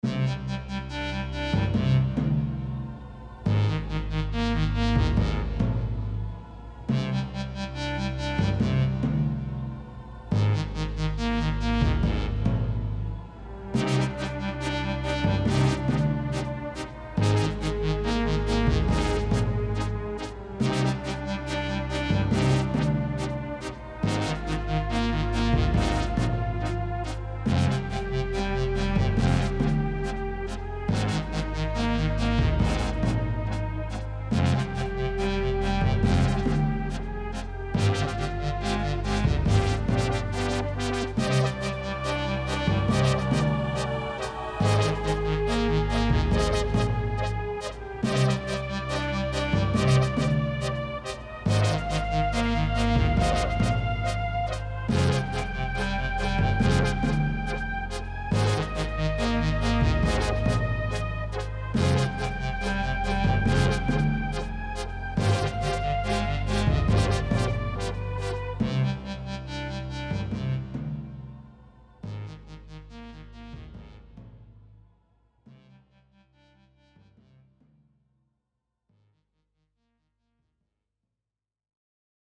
Faux Chiptune Music?
Filed under: Audio / Music, Remix, Video Game Music